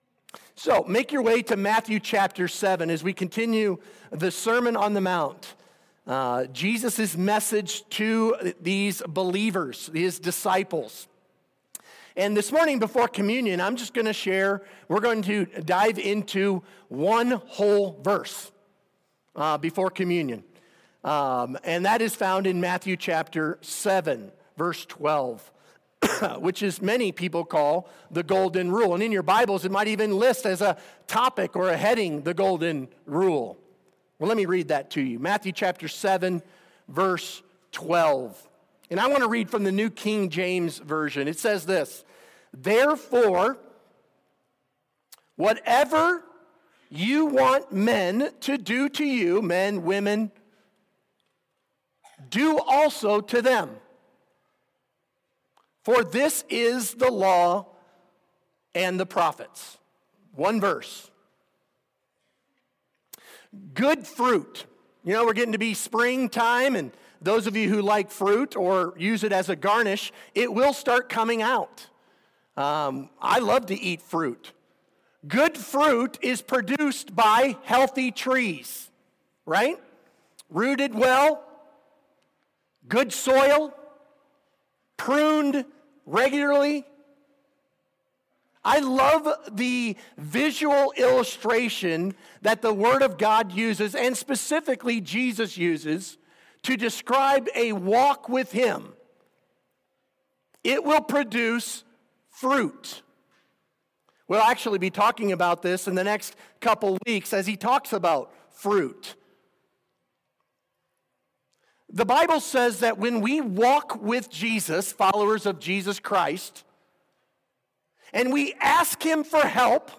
Sermon Questions Read Matthew 7:1-12.